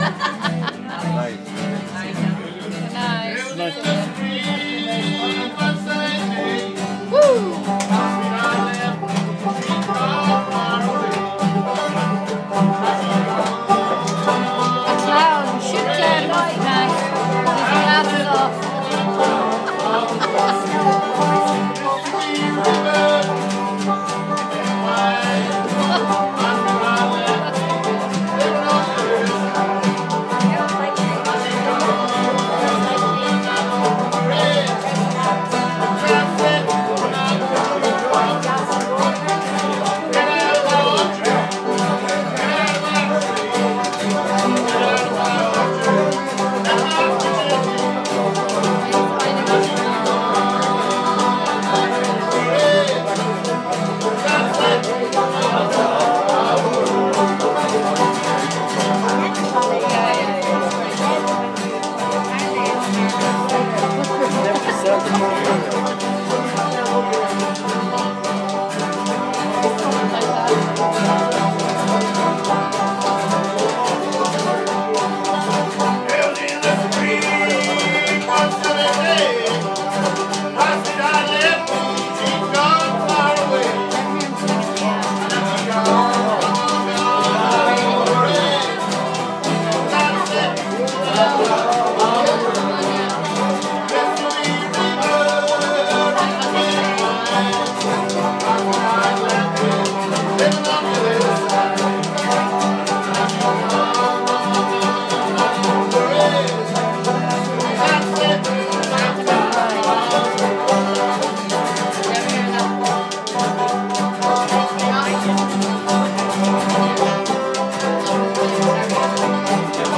Trad at Ballyboughal